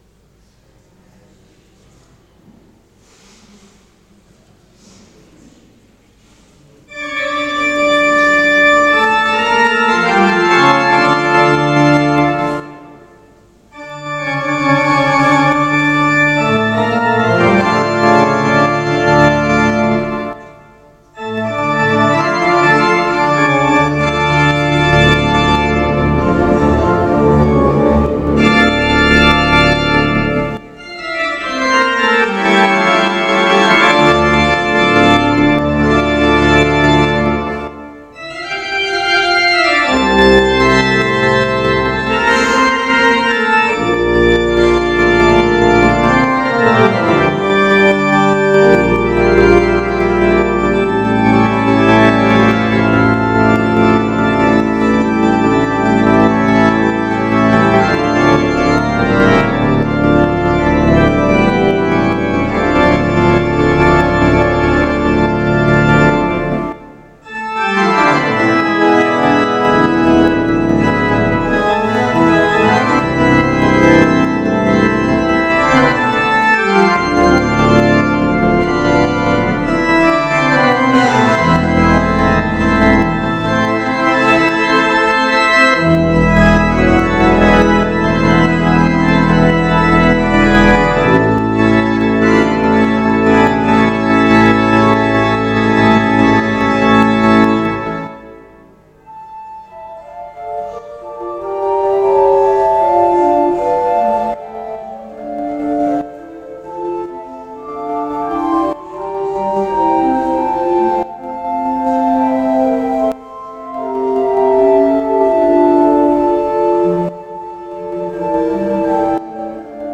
Abendgottesdienst Martinskirche Nierstein als Audio-Podcast